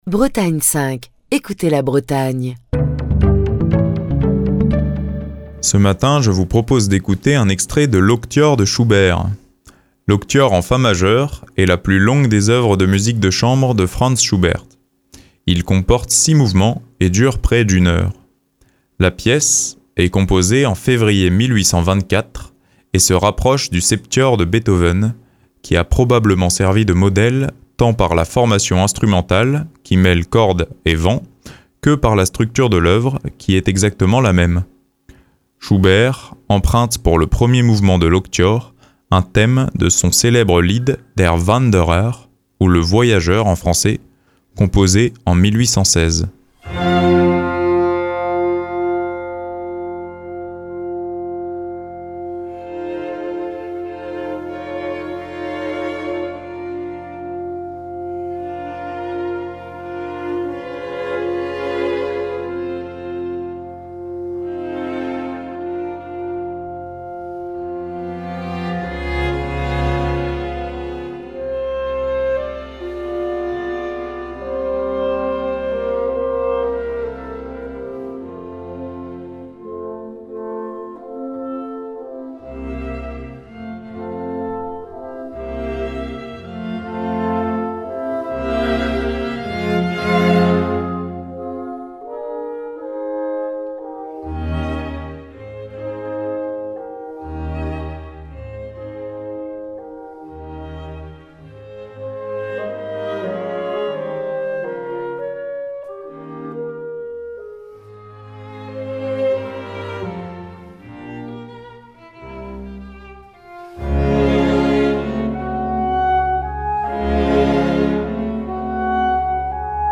un somptueux mélange de sonorités entre bois et cordes